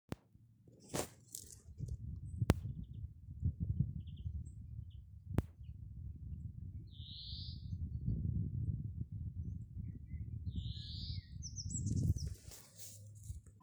Putni -> Žubītes ->
Zaļžubīte, Chloris chloris
StatussDzied ligzdošanai piemērotā biotopā (D)